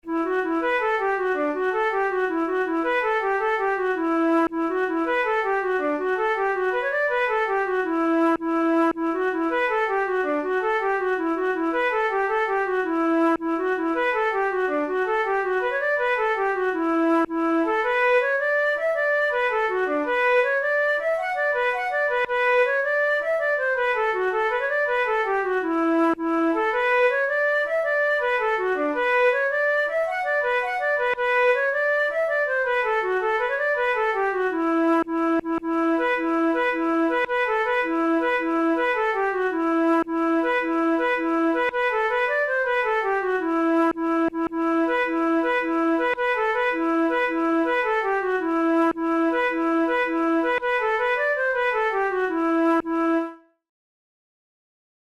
InstrumentationFlute solo
KeyE minor
Time signature6/8
Tempo108 BPM
Jigs, Traditional/Folk
Traditional Irish jig